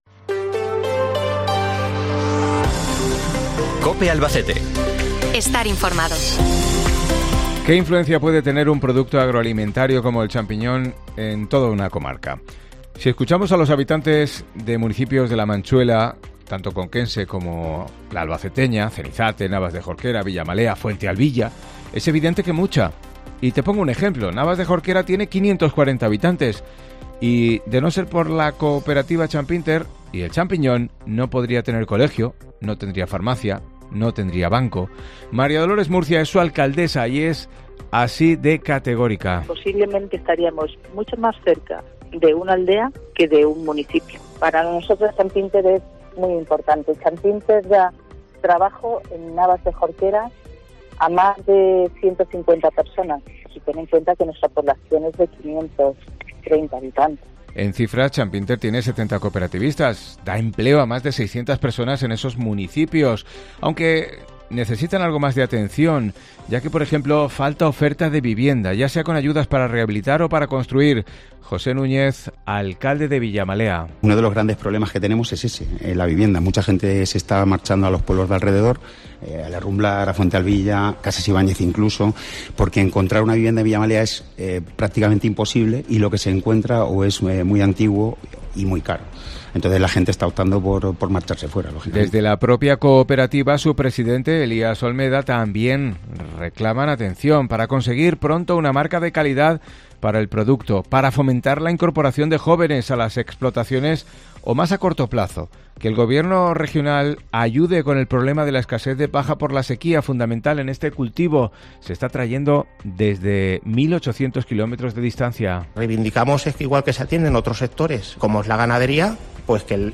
AUDIO: Escucha aquí las noticias de tu provincia en COPE Albacete